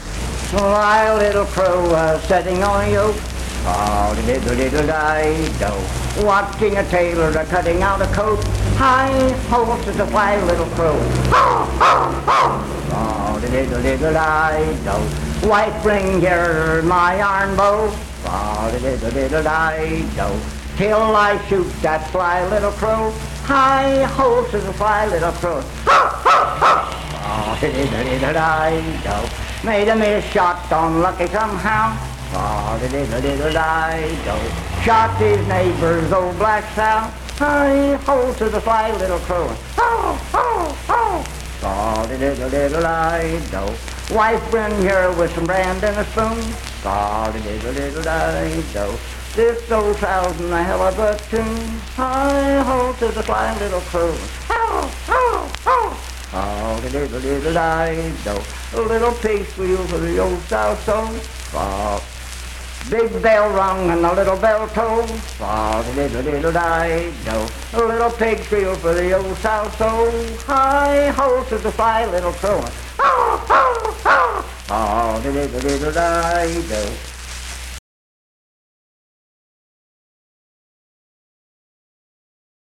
Unaccompanied vocal music performance
Verse-refrain 5 (5w/R).
Voice (sung)
Spencer (W. Va.), Roane County (W. Va.)